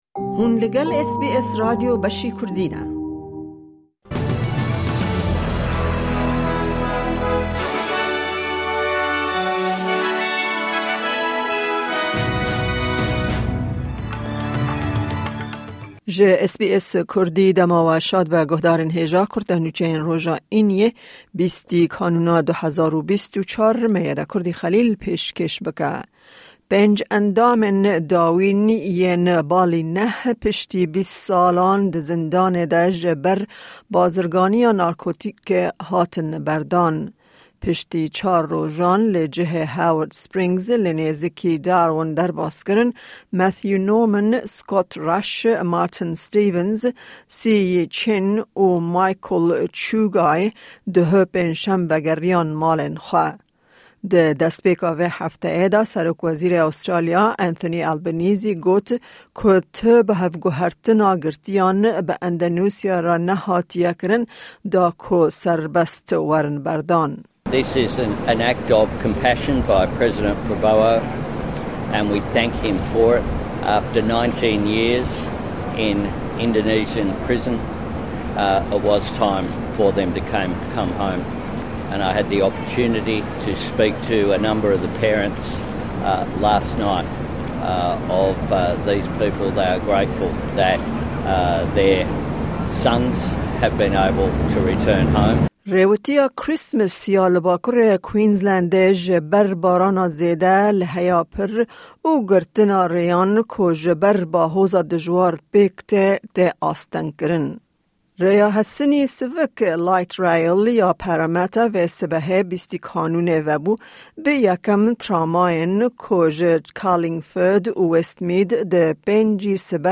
Kurte Nûçeyên roja Înî 20î Kanûna 2024